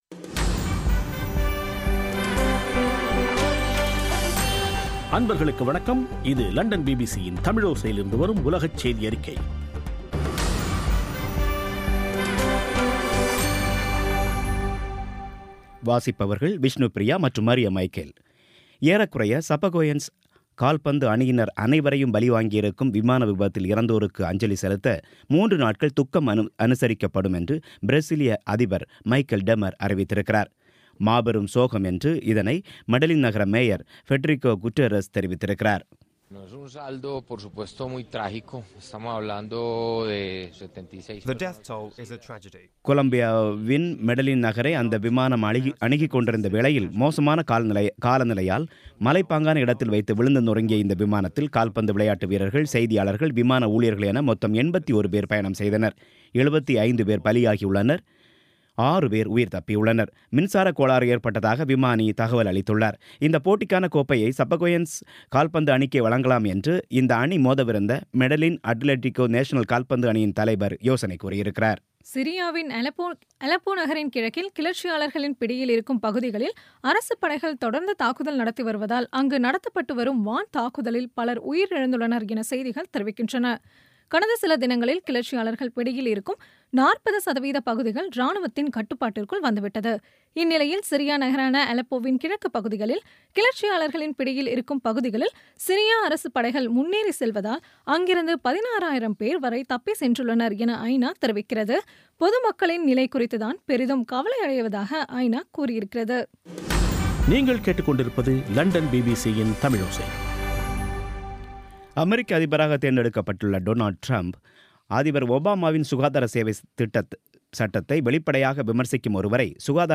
பி பி சி தமிழோசை செய்தியறிக்கை (29/11/16)